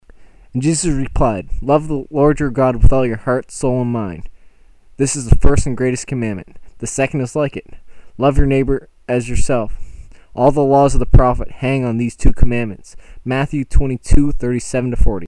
WARNING:  these MP3 files are not of the highest quality.  they have been recorded on a 7 dollar microphone bought at staples.
There is slight crackeling in some of the tracks.